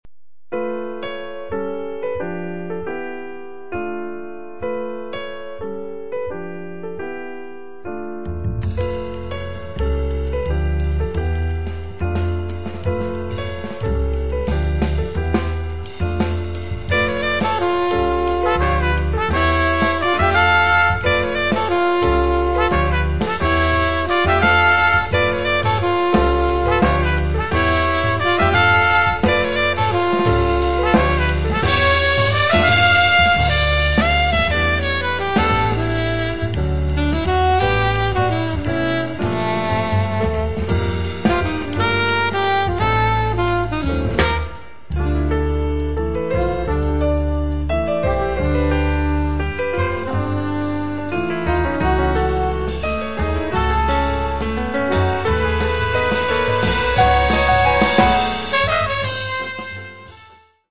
Tromba
Piano
Contrabbasso
Batteria